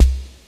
DRUMMACHINEKICK.wav